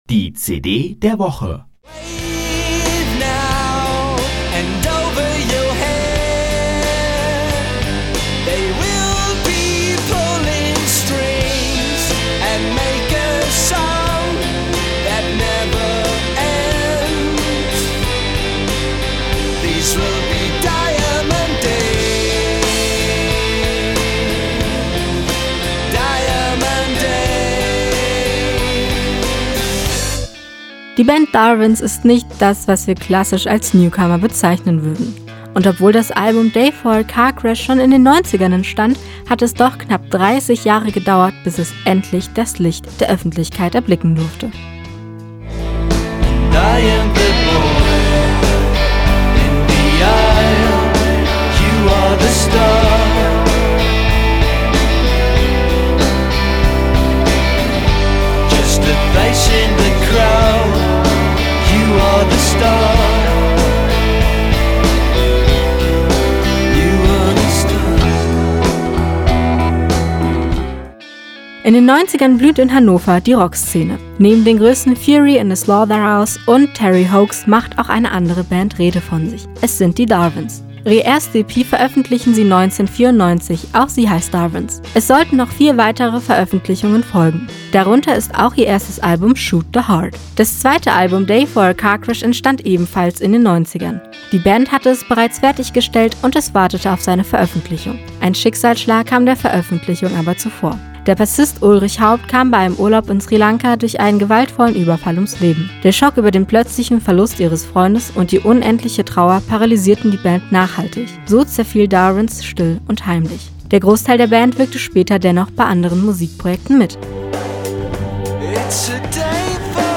CD der Woche: Darwins – Day For A Carcrash – Campusradio Jena